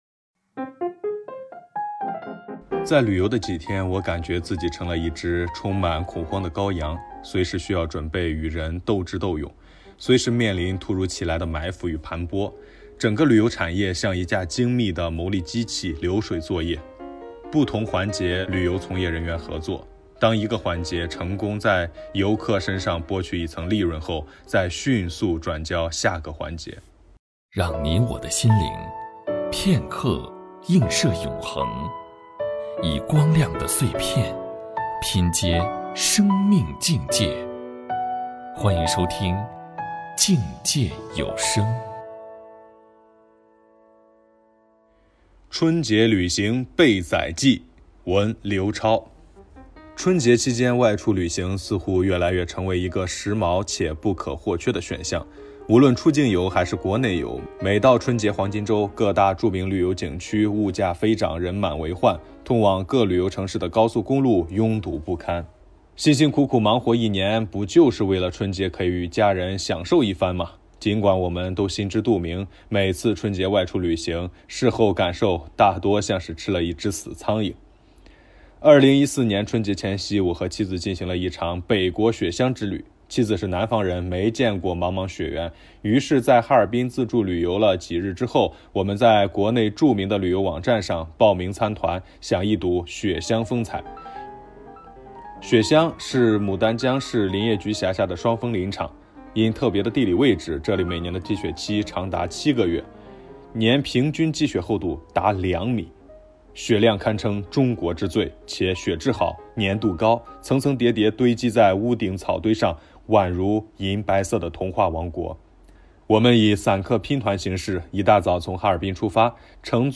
播音